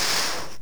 firearrowhit1.wav